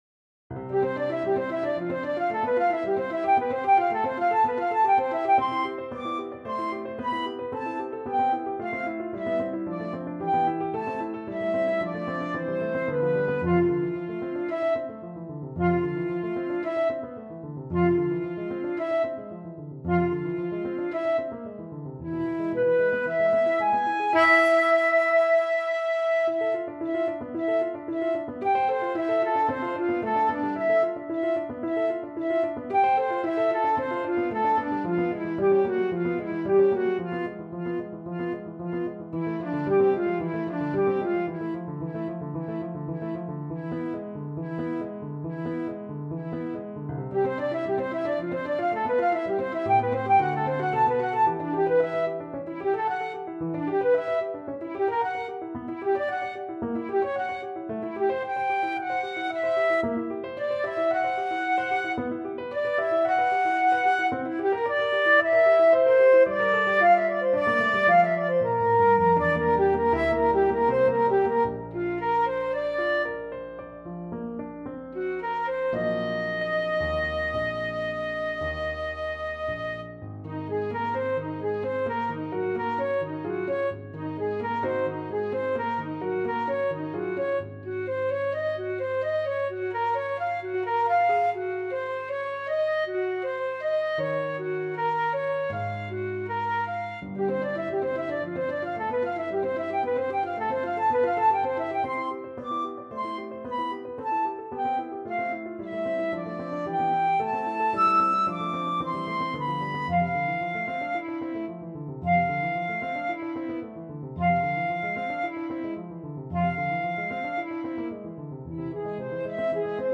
harder arrangement for Flute & Piano